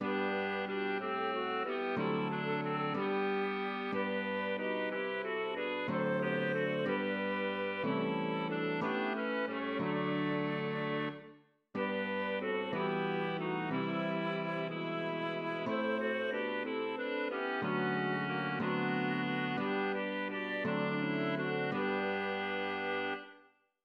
Volkslied
Johann Friedrich Reichardt wrote a version that first appeared in Liederbuch für die Jugend in 1799.[1] It followed the time's ideal of a simple art song in folk style.; he had written in 1782, in an article of the Musikalisches Kunstmagazin magazine, that it was the highest and most difficult objective of a composer to "make a song in true folk spirit" ("ein Lied in wahrem Volksinn zu machen"). His melody for the song, described as swinging and dance-like, has remained popular.[1]